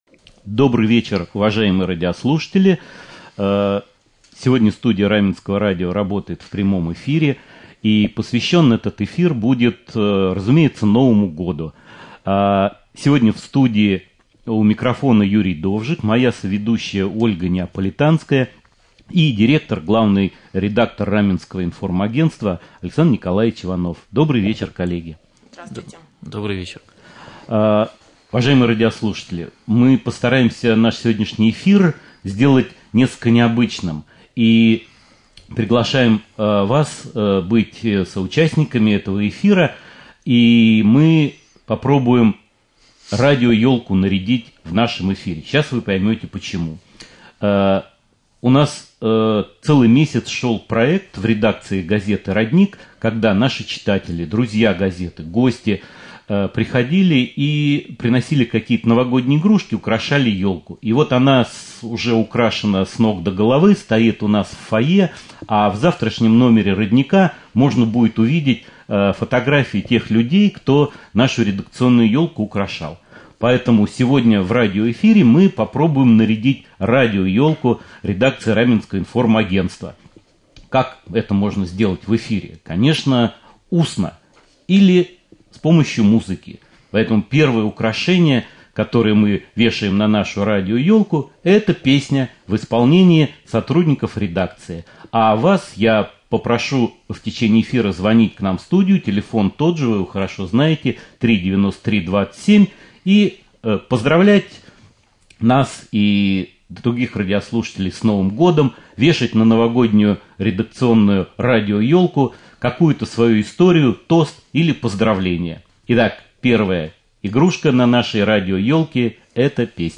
Прямой эфир, посвященный приближающемуся новому году.